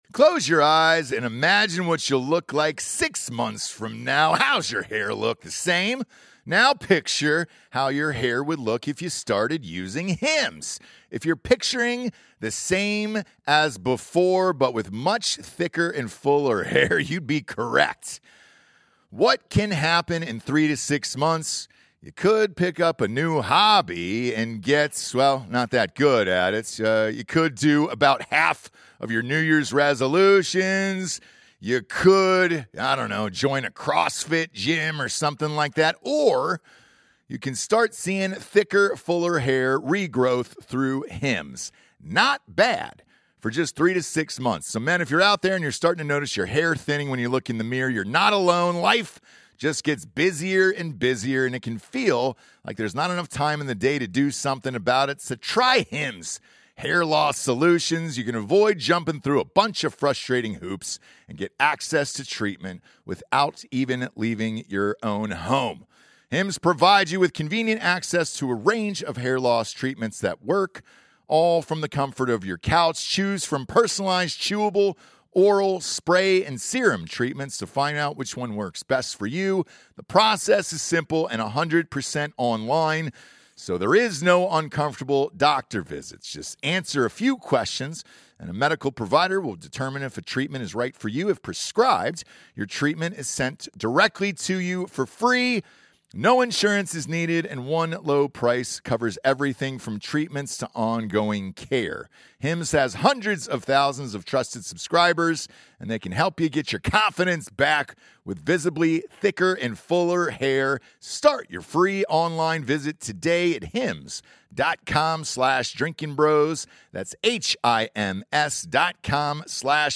Episode 594 - DB Sports Companion Show 05/06/20 - Special Guest Indianapolis Colts GM Chris Ballard